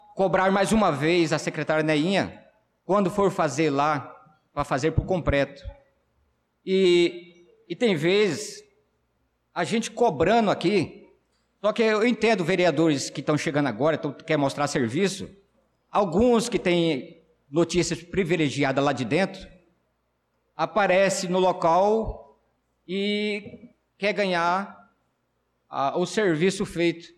Pronunciamento do vereador Naldo da Pista na Sessão Ordinária do dia 11/03/2025